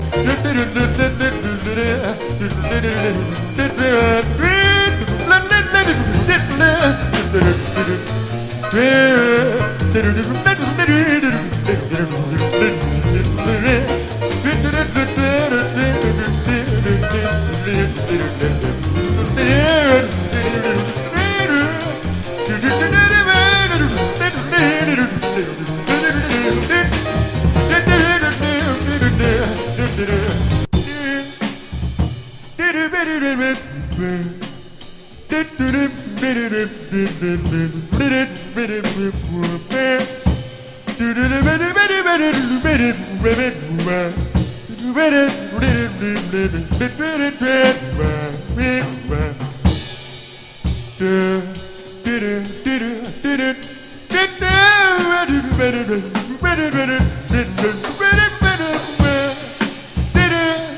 ポーランドのパーカッション奏者、兼シンガーソングライター’81年作。スキャット満載のジャズ・ボーカルアルバム。